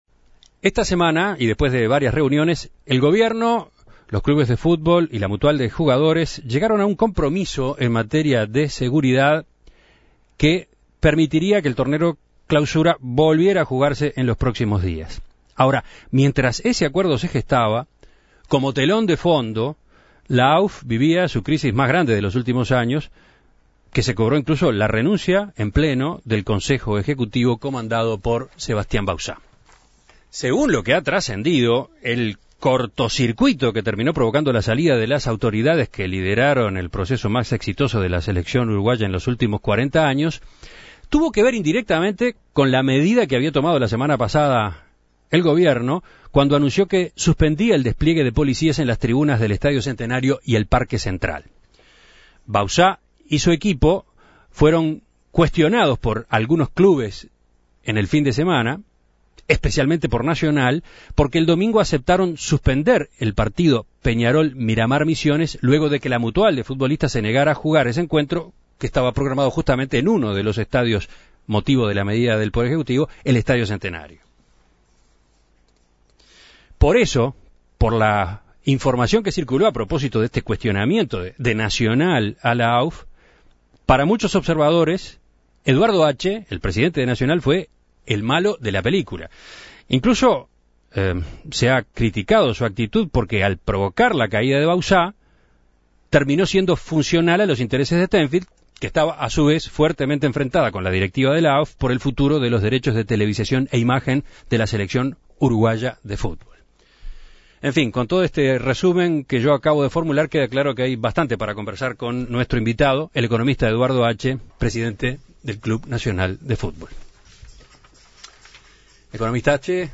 En Perspectiva lo entrevistó a propósito de su visión sobre el tema.